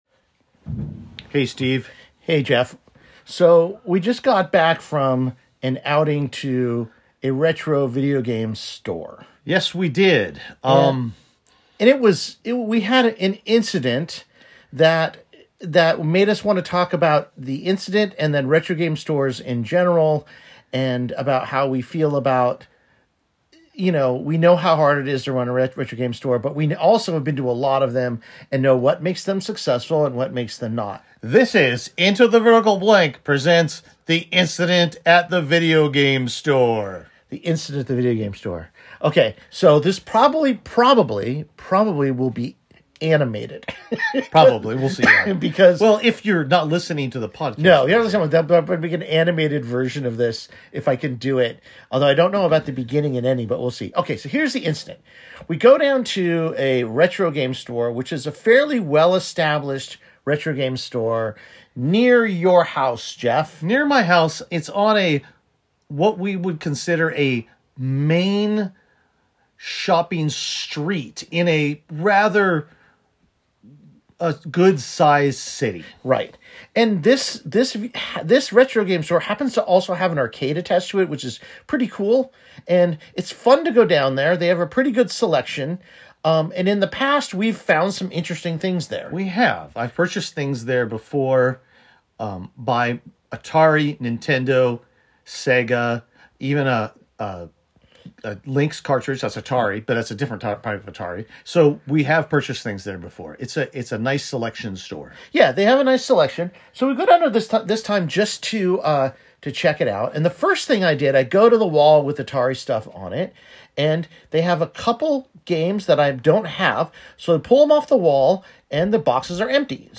In this 20 minute rant, we loudly discuss an incident we recently had at a retro game store, and then talk about how the retro game store experience could be improved for all.